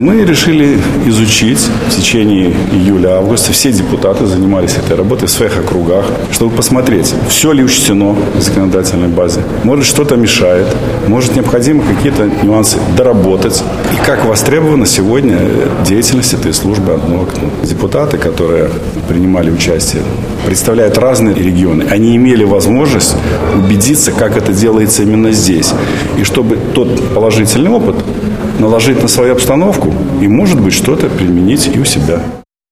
Вторая тема, которую изучили участники выездного семинара, — организация работы службы «одно окно». Ее цель — чтобы человек, которому нужен юридический документ или справка, не ходил по кабинетам разных руководителей и чиновников, а обратившись в одно место — нашел ответы на все интересующие его вопросы и получил необходимые документы, — отметил Игорь Сергеенко.